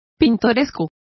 Complete with pronunciation of the translation of quainter.